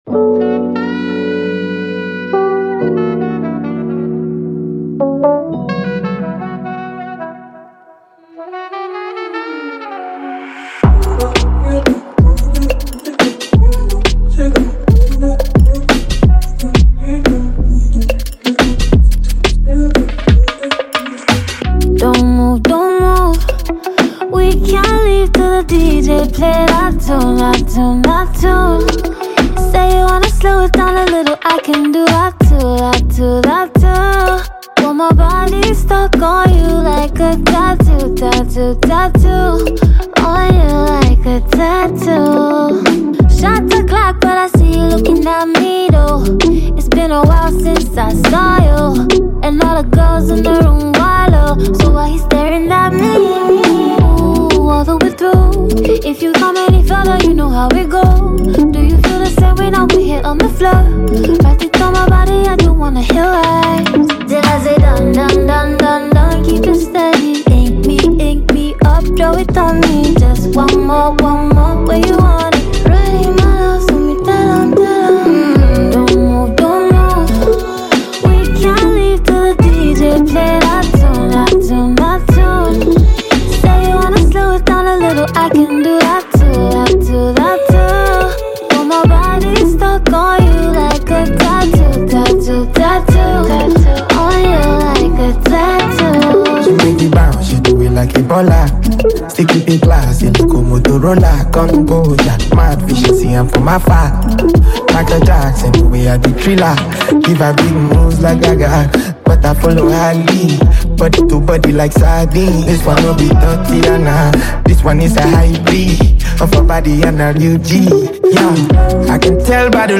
Nigerian rap sensation
sultry new single